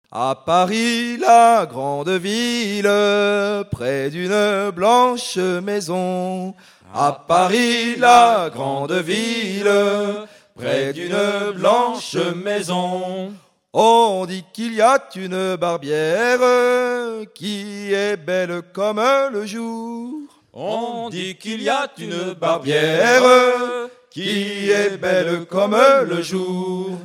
chansons traditionnelles lors d'un concert associant personnes ressources et continuateurs
Pièce musicale inédite